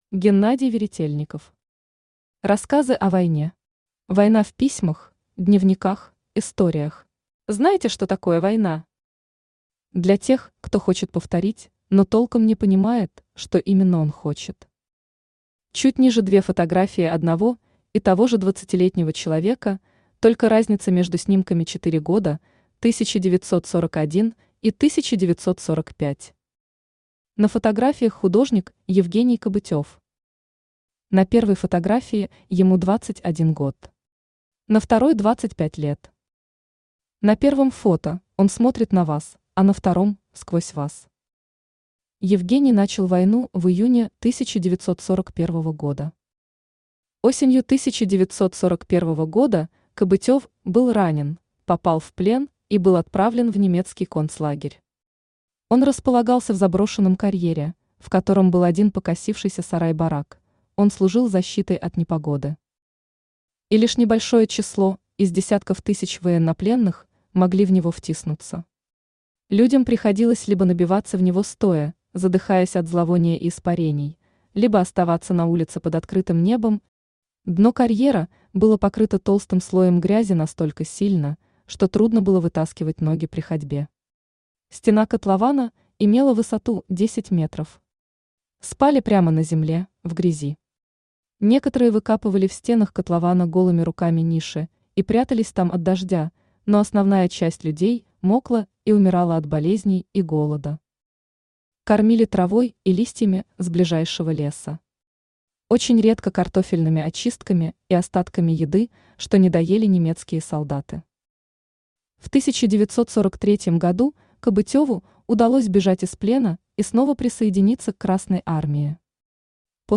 Аудиокнига Рассказы о войне. Война в письмах, дневниках, историях | Библиотека аудиокниг
Война в письмах, дневниках, историях Автор Геннадий Анатольевич Веретельников Читает аудиокнигу Авточтец ЛитРес.